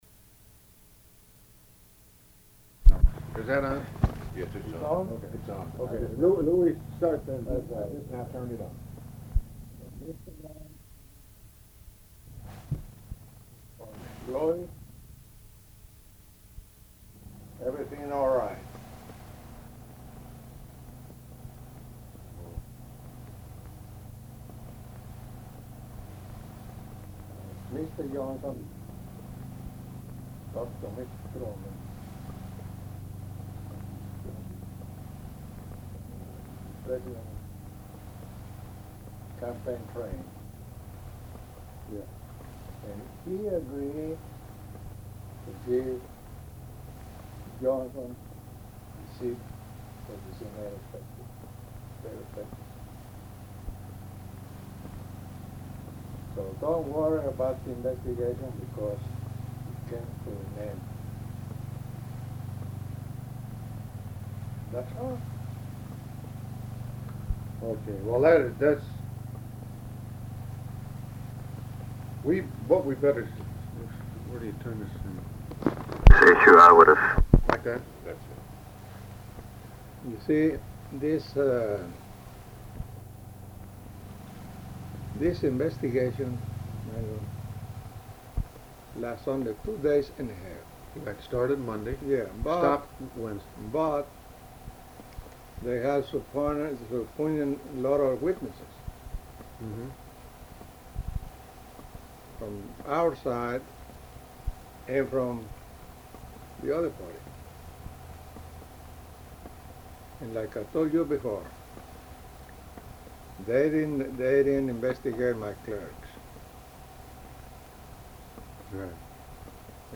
Sudden loud noise at 1:15, after which it is easier to hear
Format Audio tape
Specific Item Type Interview Subject Congressional Elections Texas